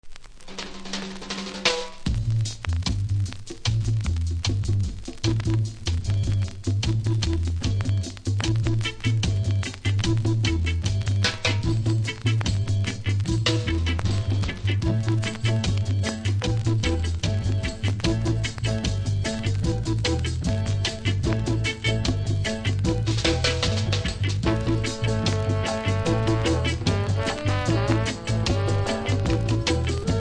キズ多めですが音は良好なので試聴で確認下さい。